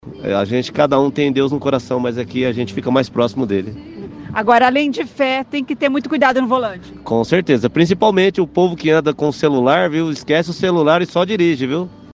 Os veículos que passam pela Avenida Tiradentes, em frente à Catedral, estão recebendo a benção do santo protetor dos viajantes.